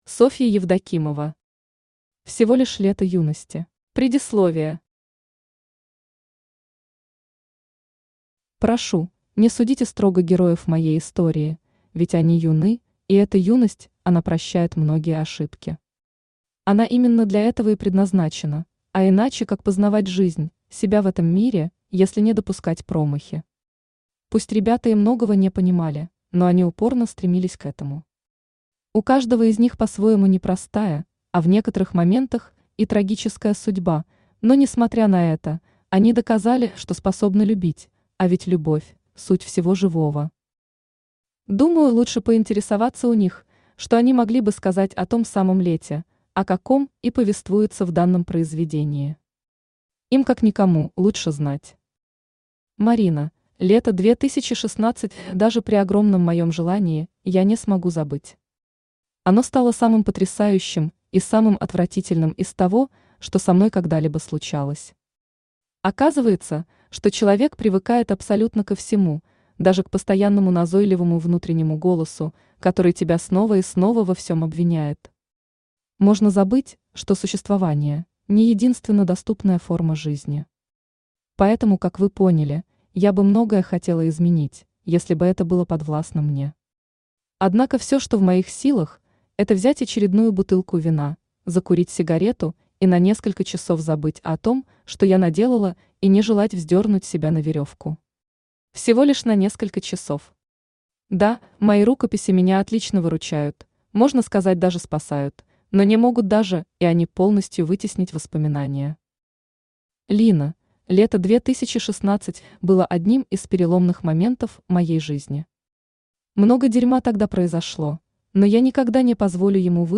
Aудиокнига Всего лишь лето юности Автор Софья Александровна Евдокимова Читает аудиокнигу Авточтец ЛитРес.